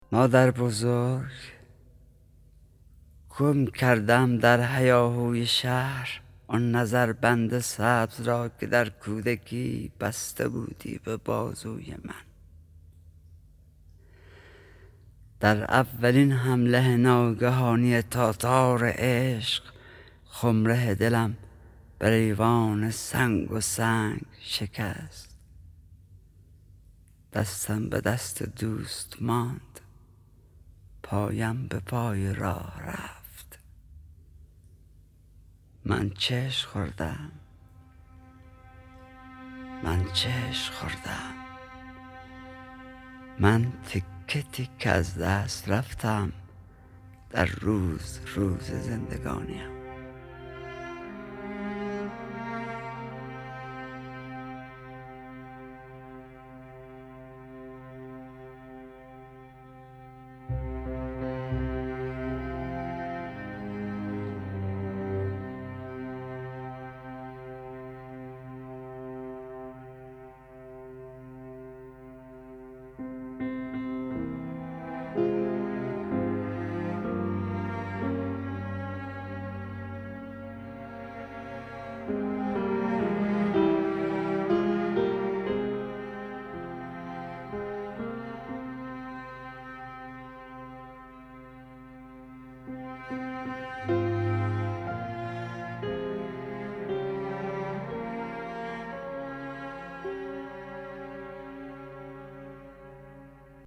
دانلود دکلمه مادربزرگ با صدای حسین پناهی با متن دکلمه
گوینده :   [حسین پناهی]